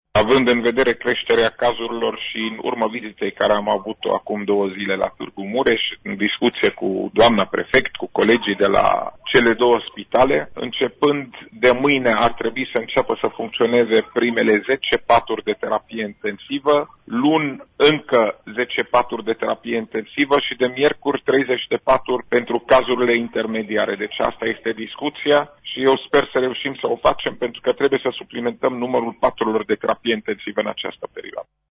Anunțul a fost făcut pentru Radio Tg Mureș de către șeful DSU, dr Raed Arafat.